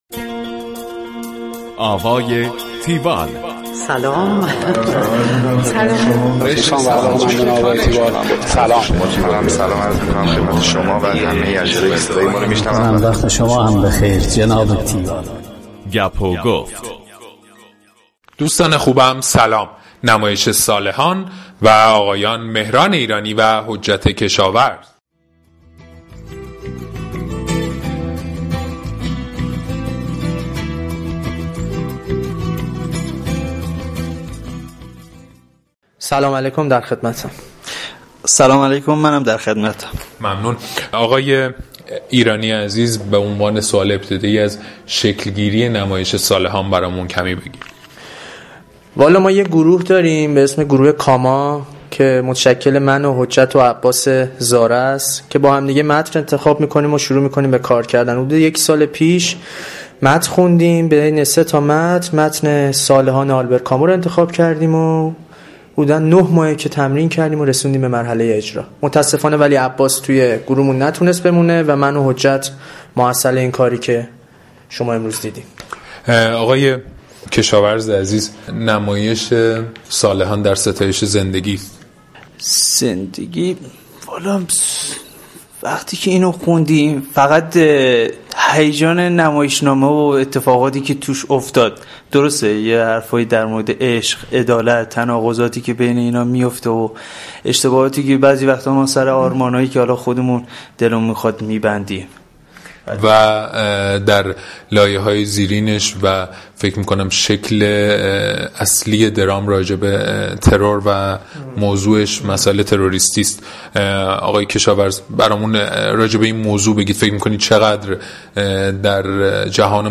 گفتگوی تیوال
tiwall-interview-salehan.mp3